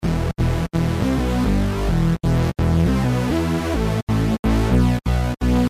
描述：短暂的合成器循环，带有一些groul，可作为许多类型的低音循环或主导，但主要是电子，技术，鼓和低音等。
Tag: 125 bpm Electro Loops Synth Loops 972.84 KB wav Key : Unknown